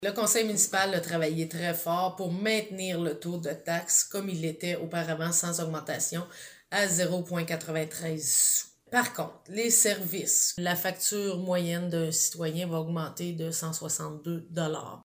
Du côté du taux de la taxe foncière, le montant prélevé pour 100 $ d’évaluation reste le même que l’an passé, à la différence des taxes de services qui seront augmentées. On écoute la mairesse, Anne Potvin :